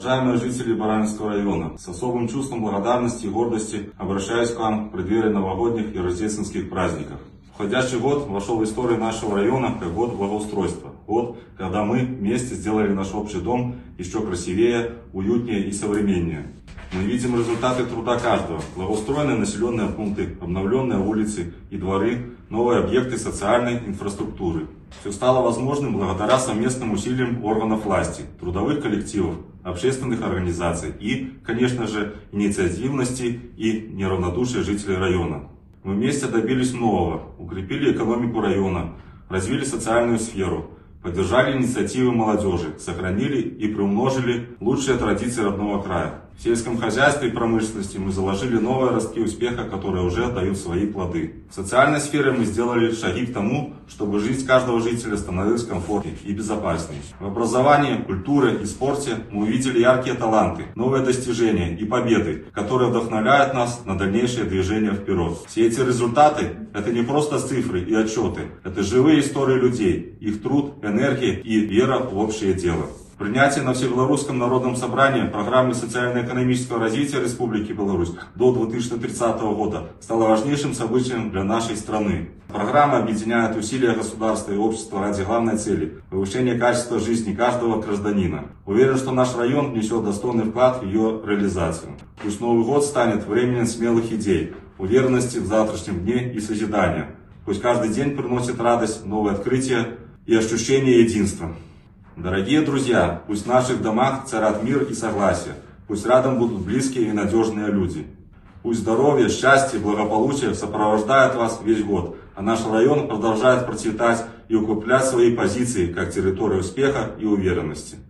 Новогоднее поздравление главы Барановичского района Романа Жука